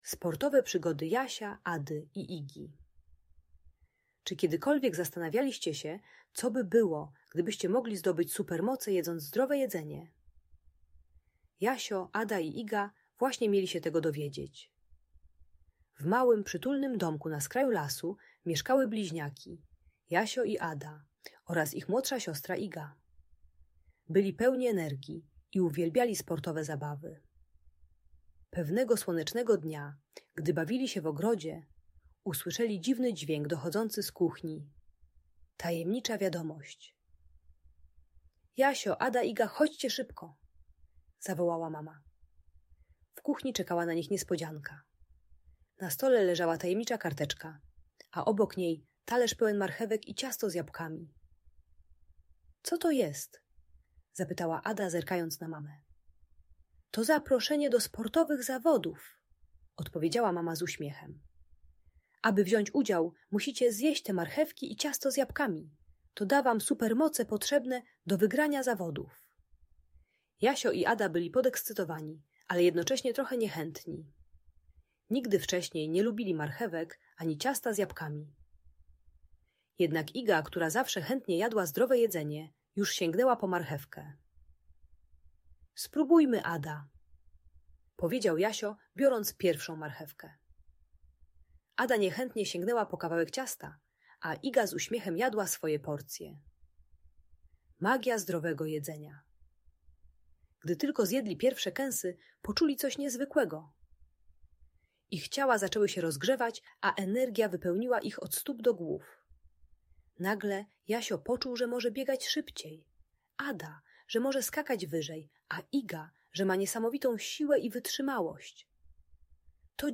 Sportowe Przygody Jasia, Ady i Igi - Audiobajka